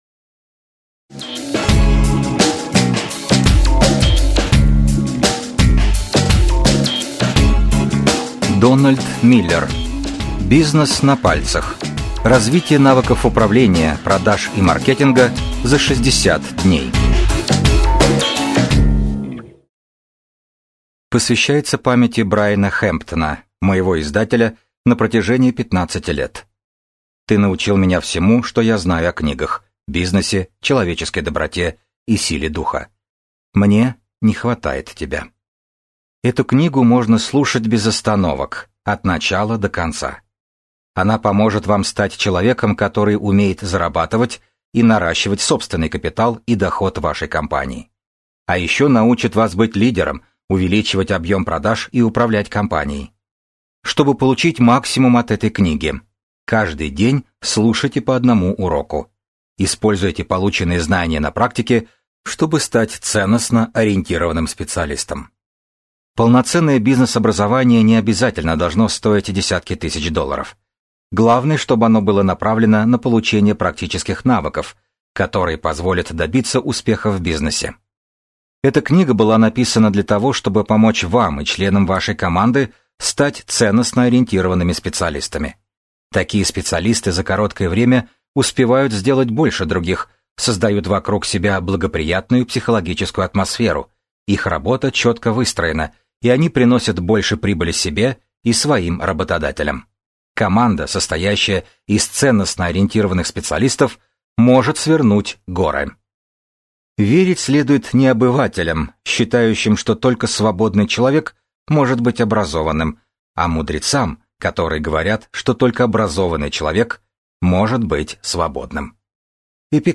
Аудиокнига Бизнес на пальцах. Развитие навыков управления, продаж и маркетинга за 60 дней | Библиотека аудиокниг